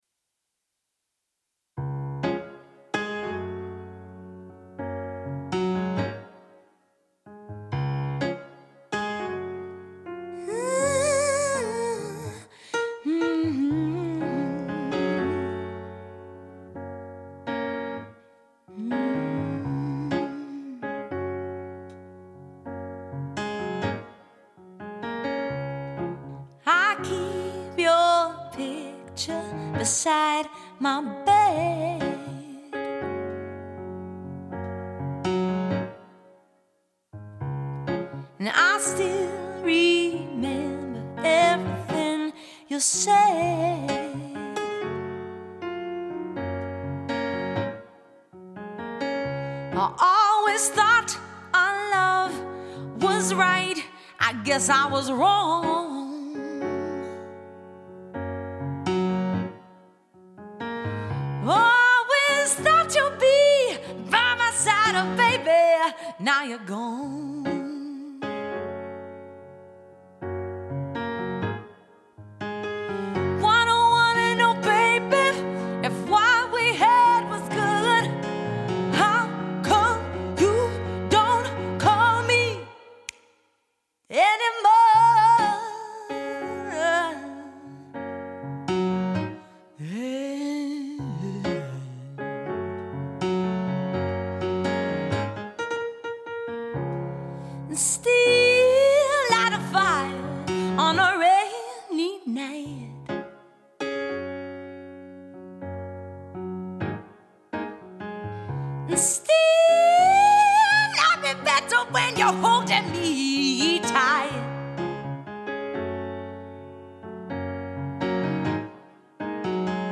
Pop, Rock, Soul,  Funk and Jazz .
Live Show Reel